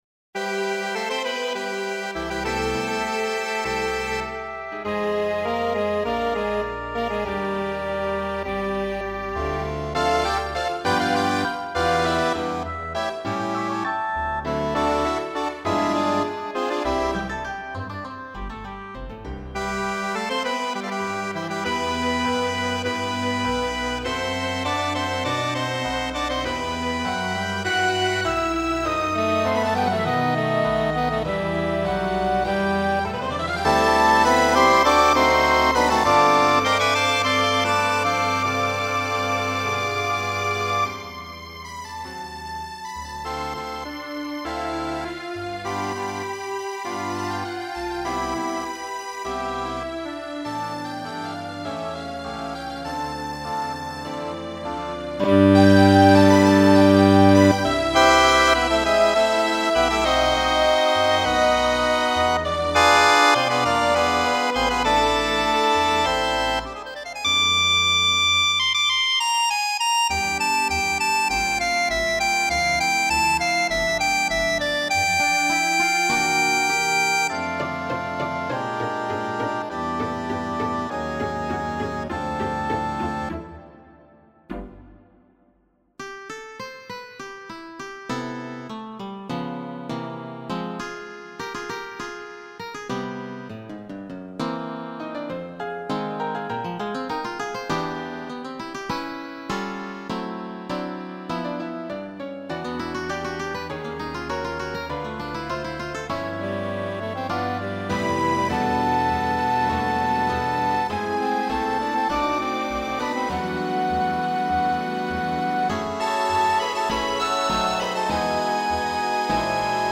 Por-la-vuelta-Tango-Orkest-NL-Meespelen.mp3